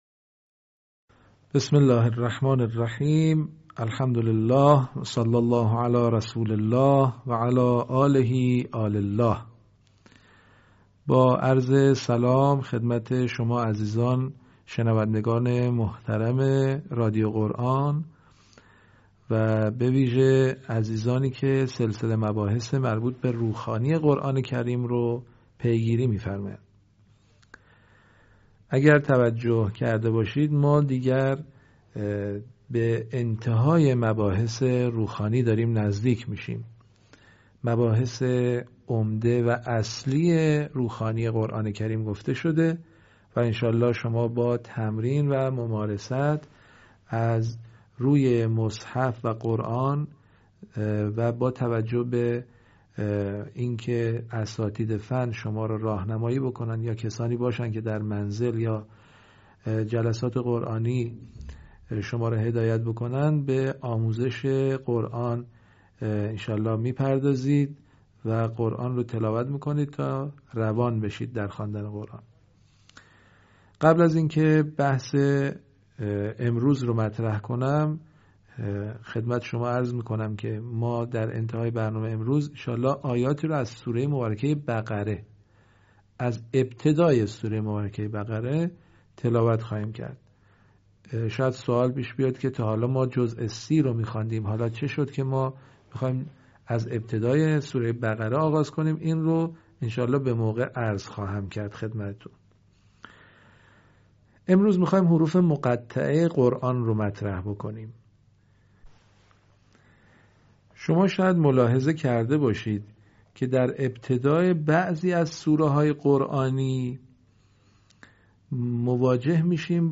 آموزش روخوانی و روانخوانی قرآن کریم